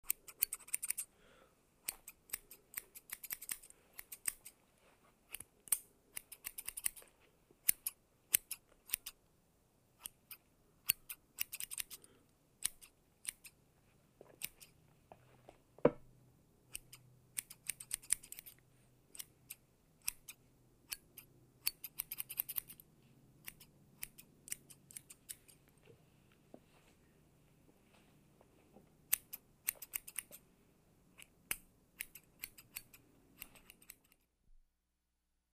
Звуки парикмахерской
Шум ножниц в парикмахерской